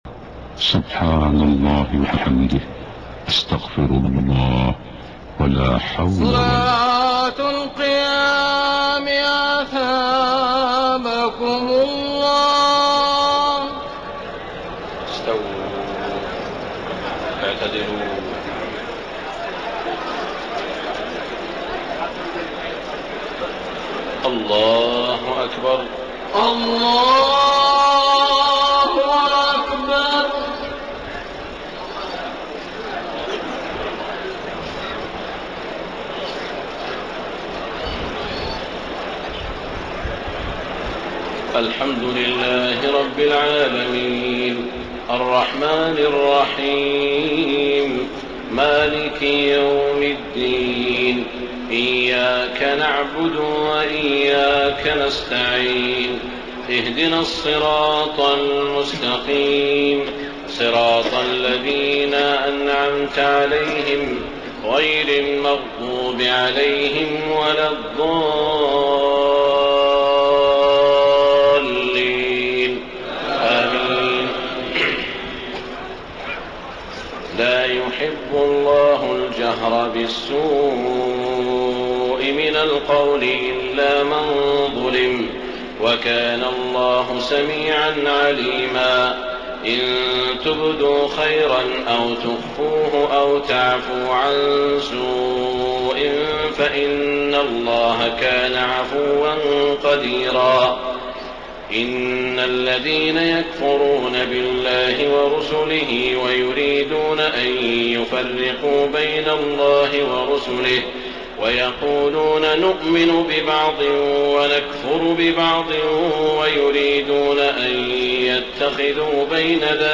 تهجد ليلة 26 رمضان 1433هـ من سورتي النساء (148-176) و المائدة (1-40) Tahajjud 26 st night Ramadan 1433H from Surah An-Nisaa and AlMa'idah > تراويح الحرم المكي عام 1433 🕋 > التراويح - تلاوات الحرمين